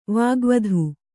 ♪ vāgvadhu